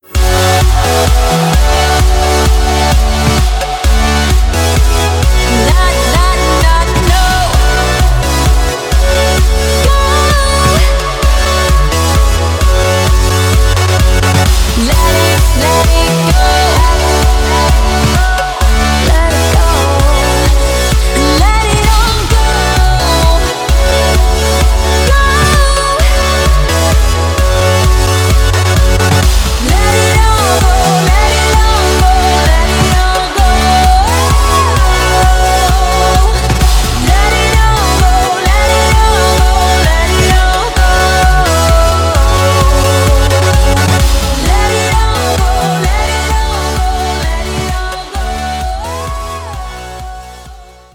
• Качество: 320, Stereo
club